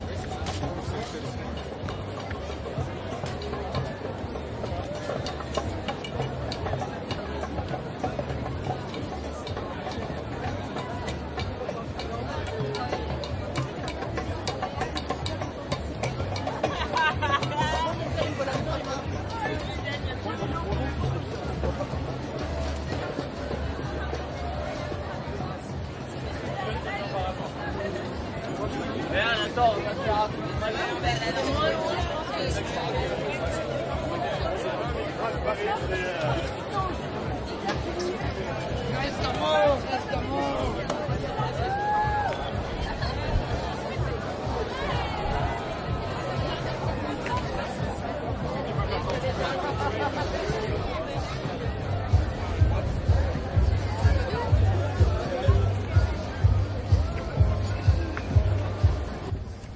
Rusting a plastic bag
I open a thin plastic shopping bag. You can hear the bag rustling.Recorded using ZOOM H2n.
标签： plastic plasticbag rusting zoomh2n bag
声道立体声